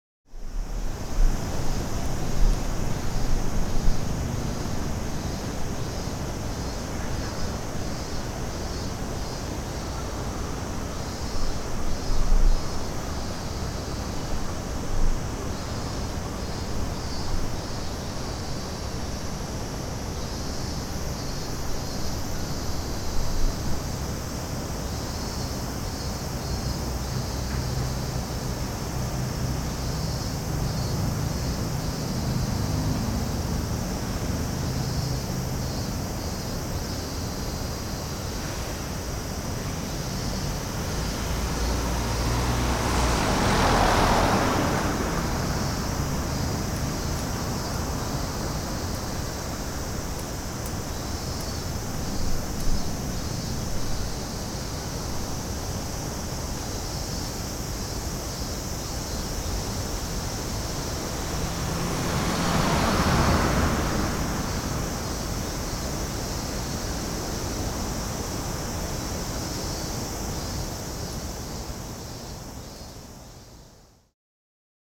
ZOOM_H1n.wav